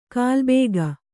♪ kālbēga